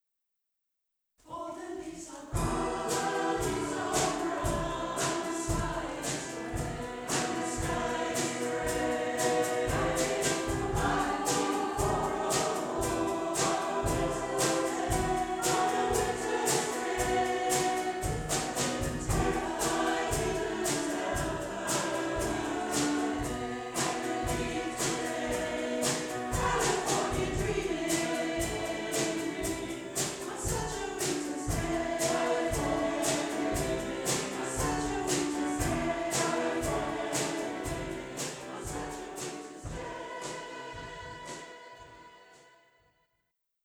We are one of the largest mixed gender choirs in the area
A selection of audio excerpts from various performances by the Barry Community Choir, recorded at venues across South Wales.
California-Dreamin-LIVE.wav